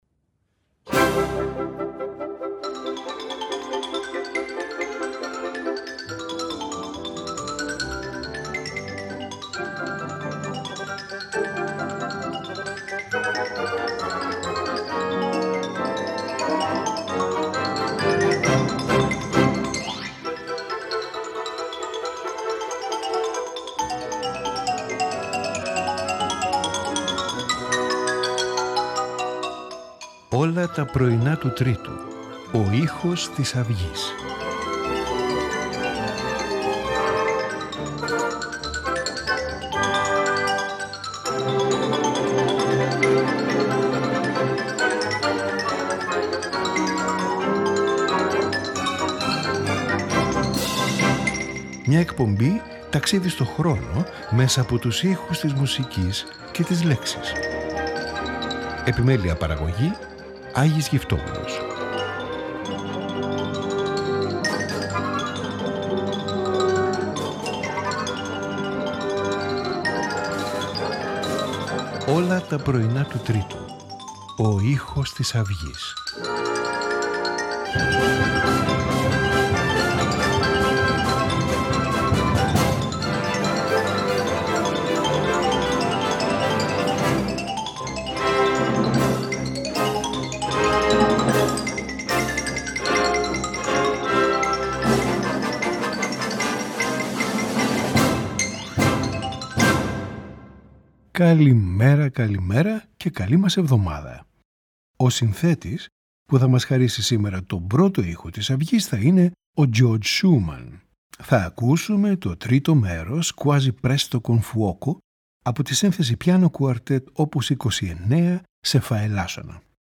Piano Quartet in F minor
Symphony No.3 in F major
Sonata for Violin and Piano No.2 in D major
Concerto in F major for Two Horns and Strings
Fantasia and Fugue in A minor